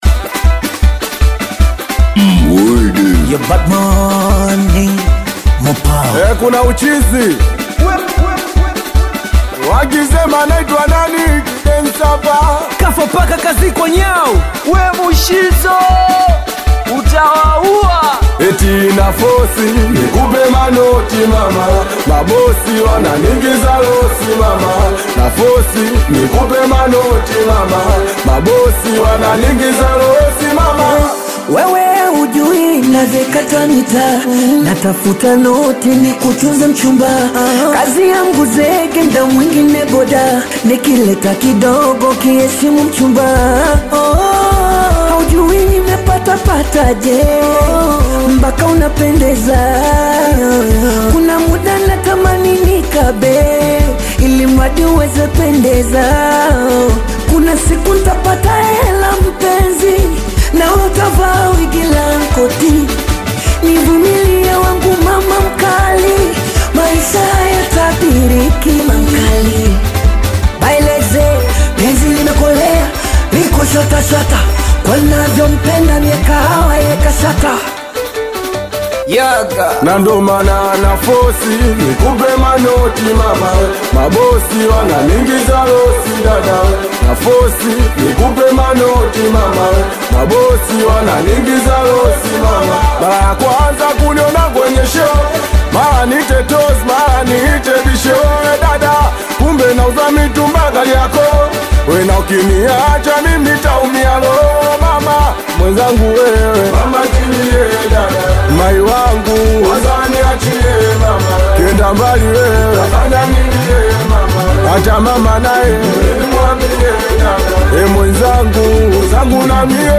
is a vibrant Bongo Flava single released on January 23
fast-paced rhythms and expressive vocals